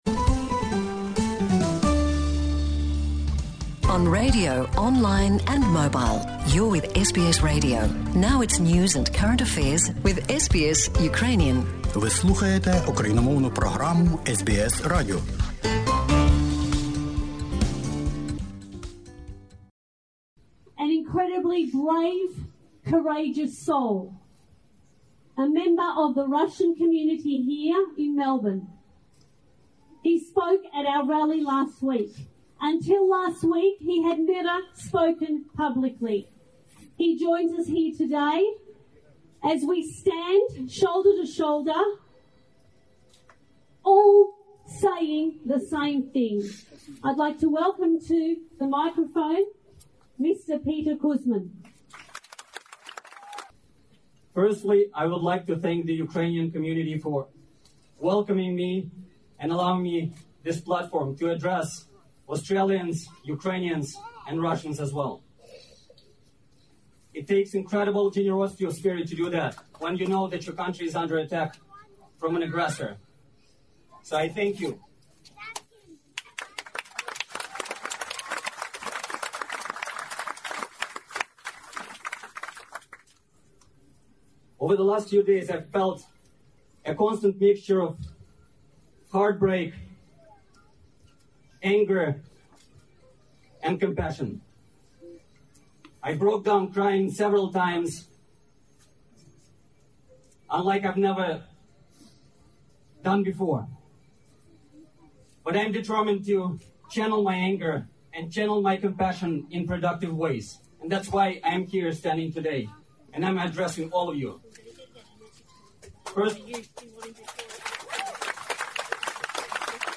Anti-war protest in Melbourne against invasion of Ukraine, 27/02/2022.